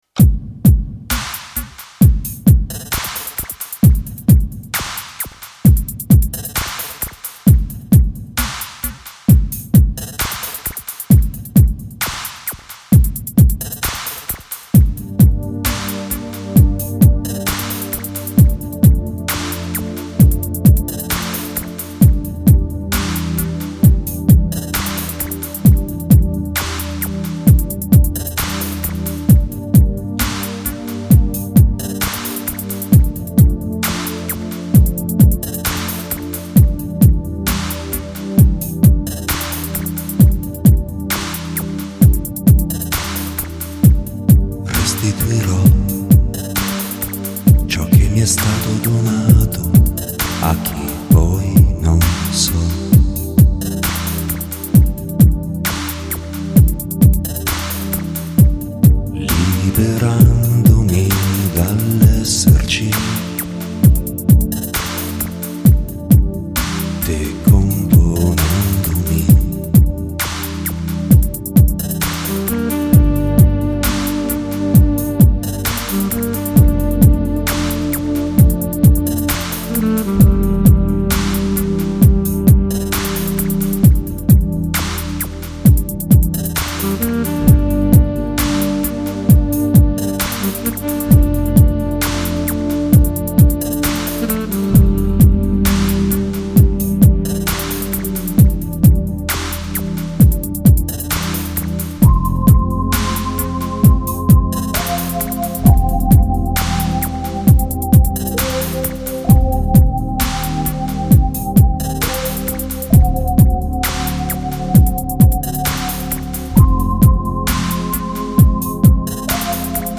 File under Dark wave